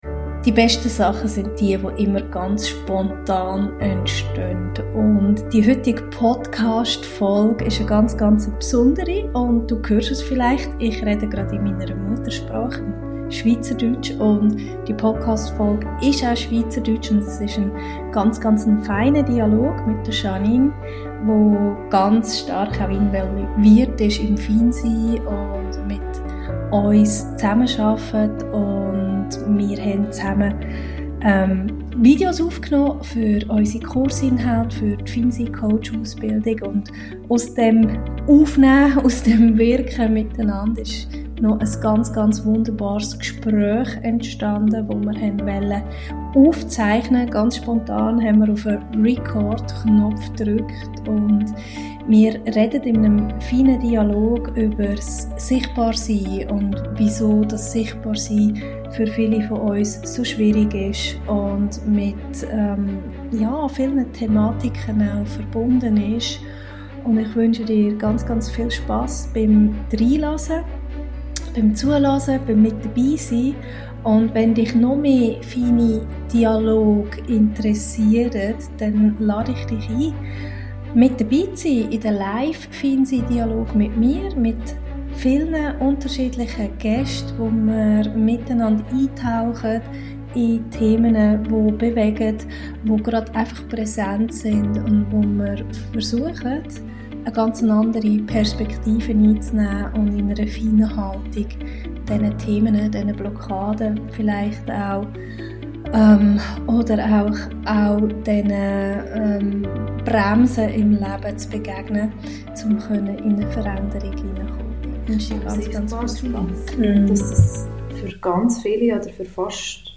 auf Schweizerdeutsch